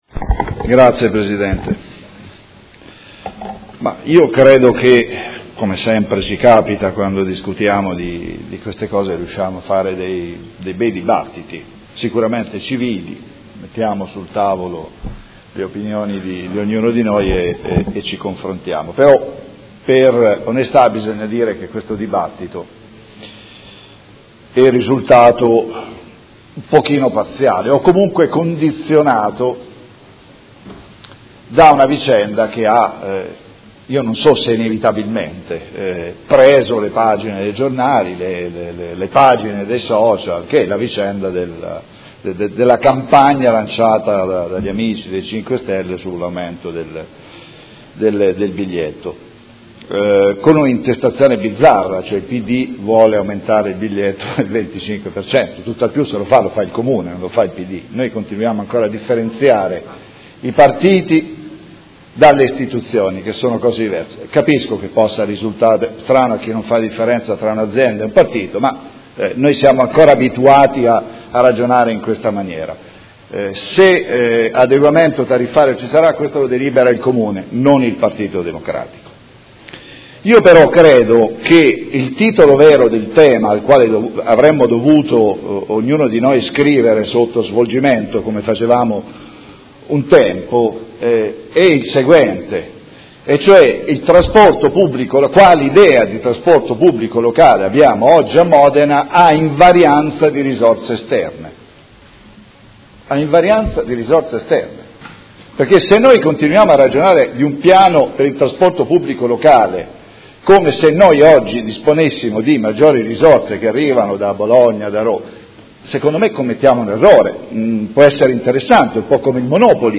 Seduta del 21/07/2016 Proposta di deliberazione: Indirizzi per la gestione del Trasporto Pubblico Locale – Adeguamenti tariffari per il Comune di Modena a decorrere dal 01.08.2016. Dibattito